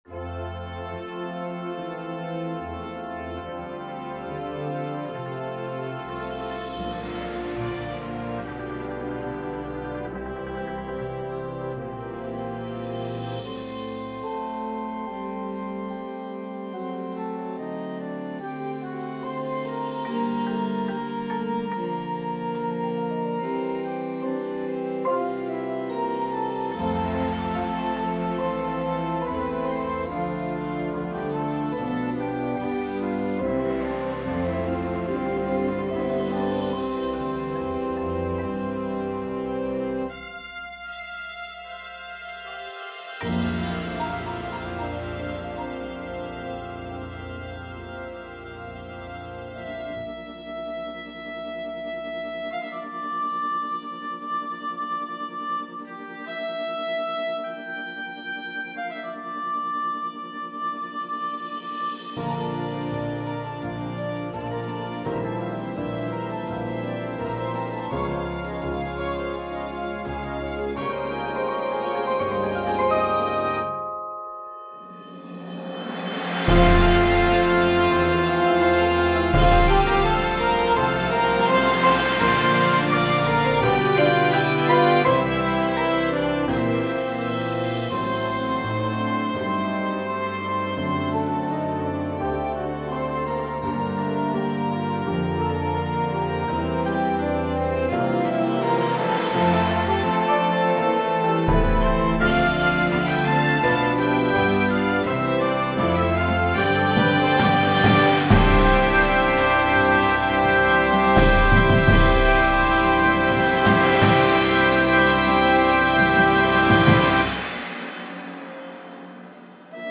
• Flute
• Trumpet 1
• Tuba
• Snare Drum
• Bass Drums